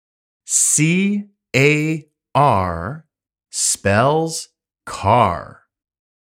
単語の読み方・発音